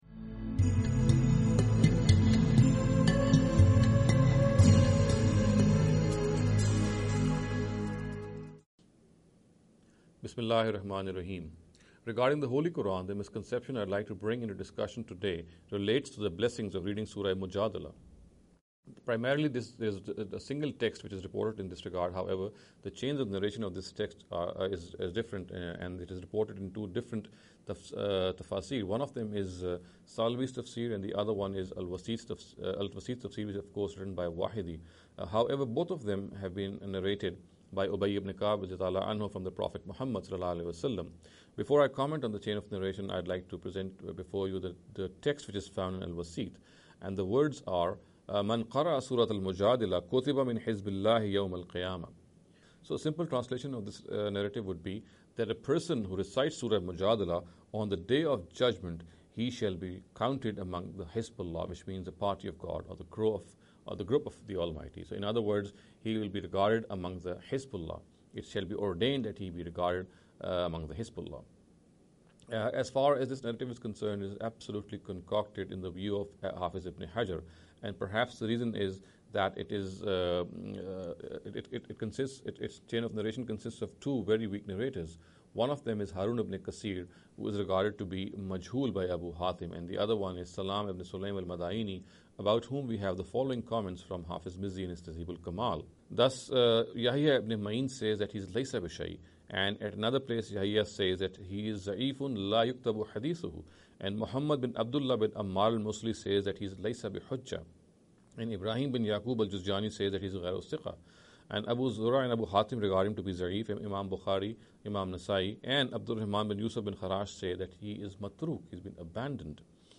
This lecture series will deal with some misconception regarding the Holy Quran.